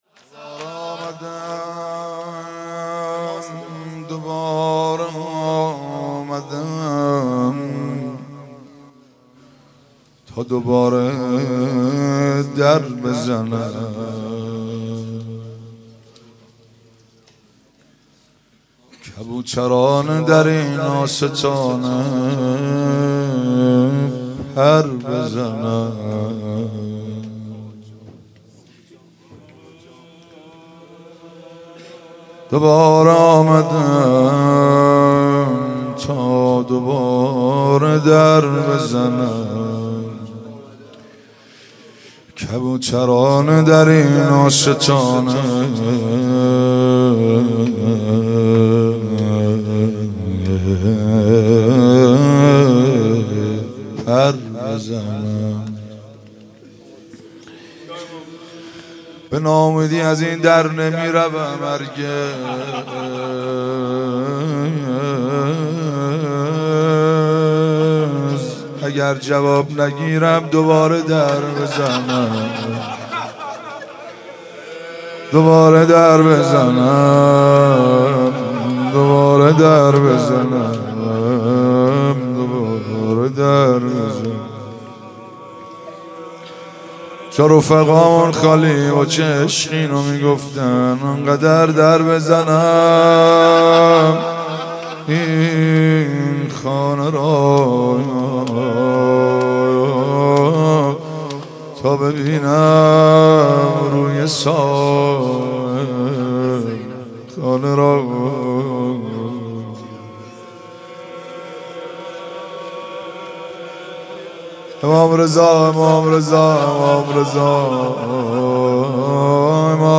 حاج مهدی سلحشور/مناجات با امام رضا(ع)